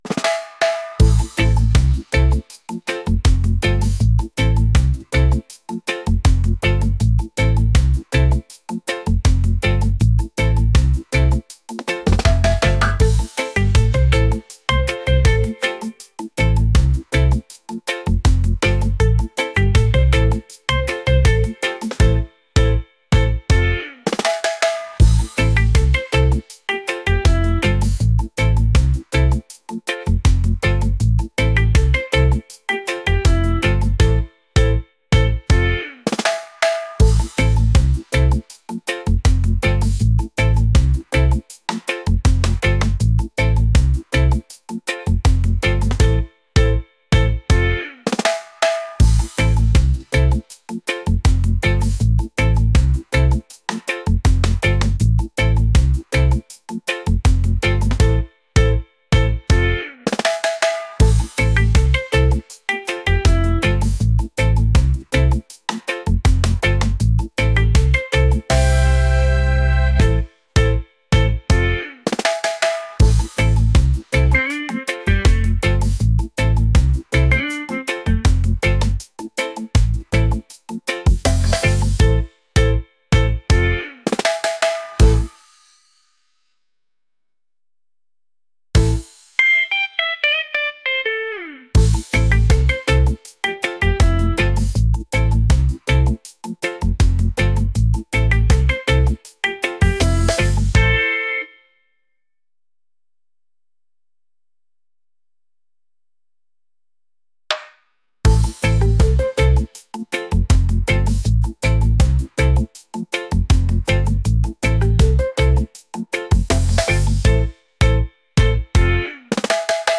island | vibes | reggae | upbeat